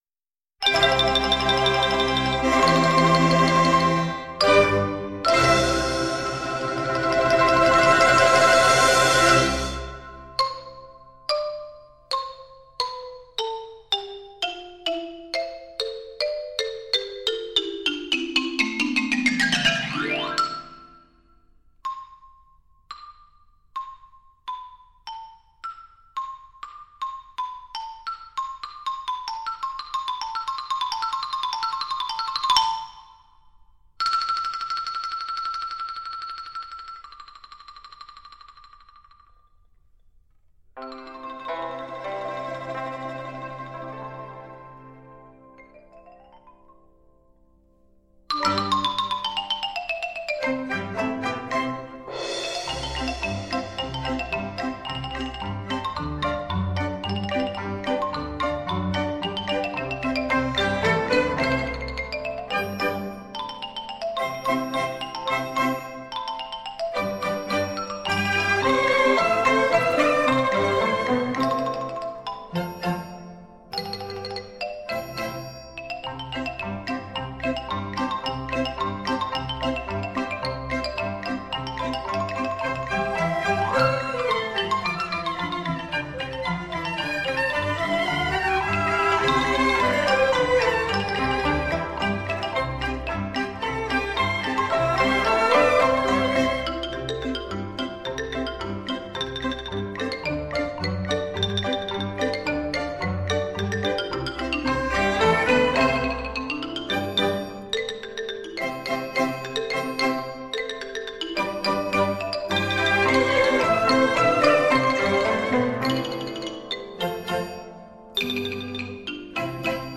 打击乐天碟的高端终极版  全面体现鼓弦交织的完美魅力